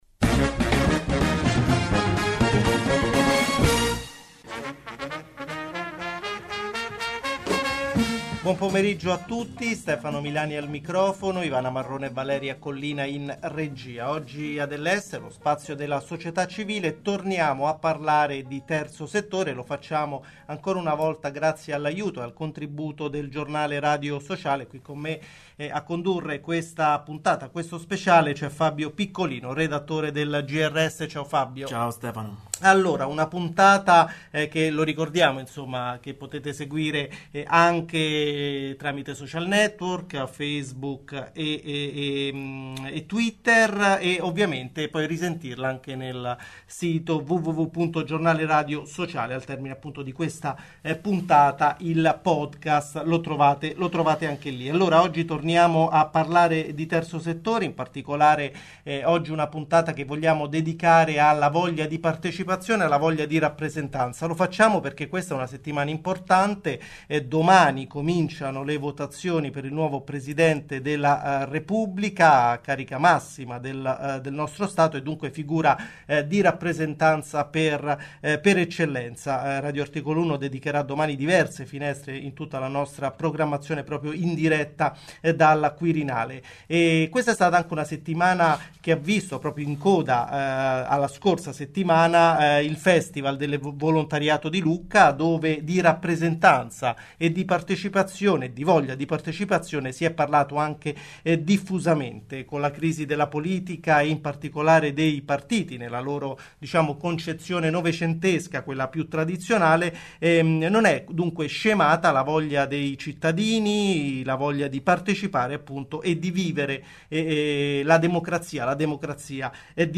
In cerca di rappresentanza Prosegue la nuova serie di speciali del Giornale Radio Sociale in collaborazione con Radio Articolo 1, per cercare di approfondire la complessa situazione internazionale alla vigilia dell’elezione del Presidente della Repubblica e a margine del Festival del Volontariato di Lucca. In studio